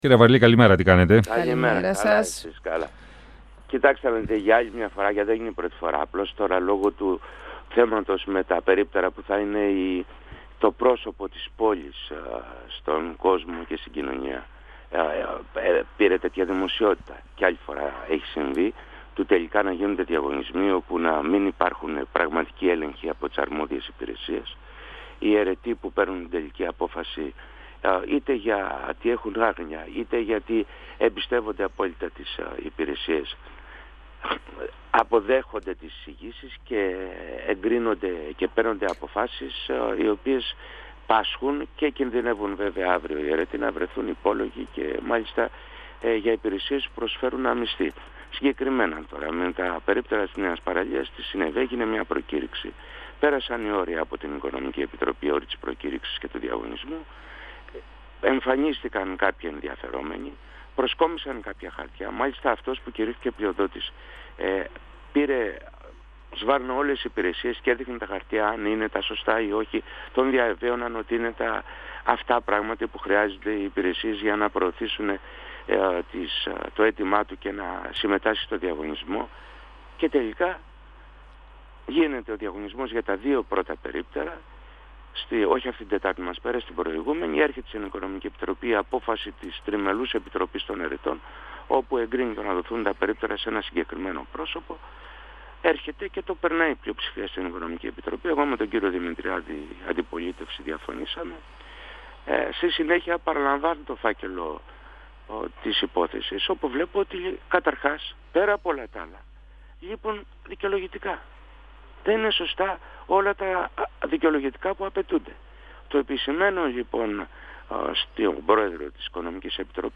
Σε ακύρωση της πρόσφατης απόφασής της θα προχωρήσει στην επόμενη συνεδρίασή της η Οικονομική Επιτροπή του δήμου Θεσσαλονίκης σχετικά με το διαγωνισμό για την εκμίσθωση δύο γυάλινων περιπτέρων στη Νέα Παραλία. Η επιτροπή κατακύρωσε την εκμίσθωση στον πλειοδότη των διαγωνισμών αλλά ο φάκελος ήταν ελλιπής, επισήμανε ο δημοτικός σύμβουλος Γιώργος Αβαρλής, μιλώντας στον 102FM του Ραδιοφωνικού Σταθμού Μακεδονίας της ΕΡΤ3.